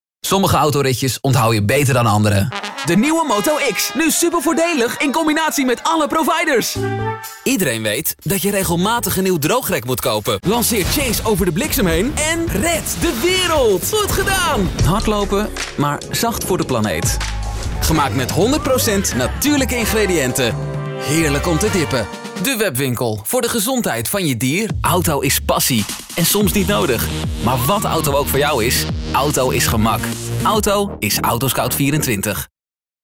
Der hollĂ€ndische Voice-Over tĂ¶nt frisch und begeistert. Seine Stimme wirkt jung, aber meist reif, so ideal fĂŒr jede Altersgruppe.
Sein Heimstudio hat die beste KlangqualitĂ€t, auch auf Grund der Anwesenheit einer Sprechkabine.
Sprechprobe: Werbung (Muttersprache):
Demo - Commercials.mp3